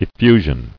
[ef·fu·sion]